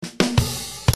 SAMPLES DE BATERIA
¿Te gustan los samplers de ritmos y redobles de batería?, aquí tengo unos cuantos bajados de Internet a tu disposición en formato mp3.
6_8 Fill 06.MP3